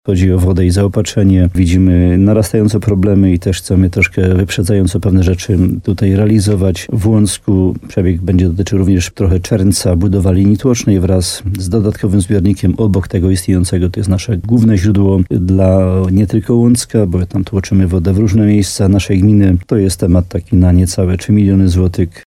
Wójt Jan Dziedzina nie ukrywa, że jedną z priorytetowych kwestii w nowym roku będzie nowa sieć wodociągów.